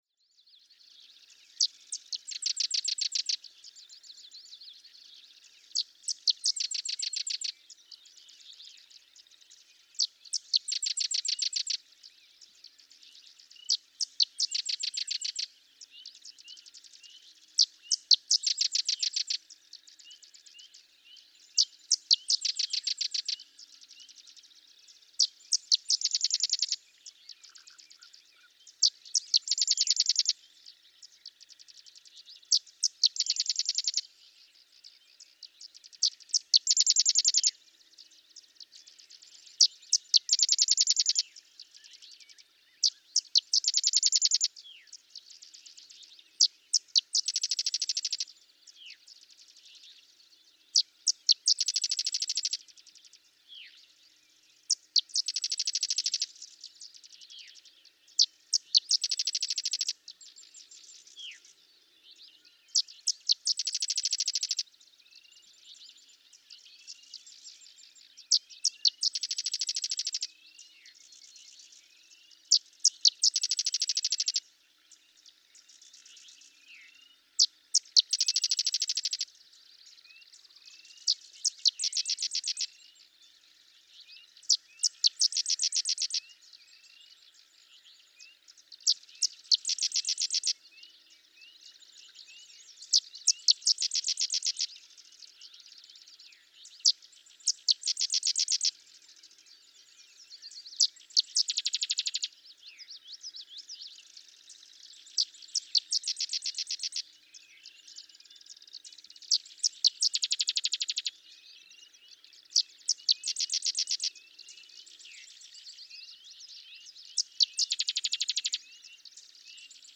Sedge wren
Thirty-six songs. He switches to a new song at 0:25, 0:46, 1:21, and 1:52.
Sax-Zim Bog, Minnesota.
407_Sedge_Wren.mp3